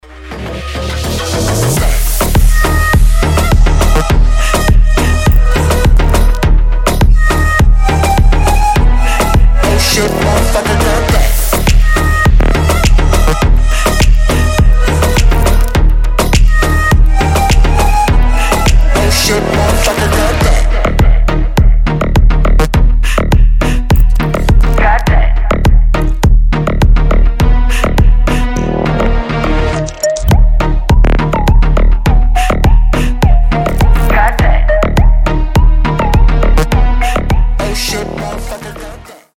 • Качество: 320, Stereo
жесткие
мощные басы
восточные
качающие
G-House
Mashup
Крутой восточный трап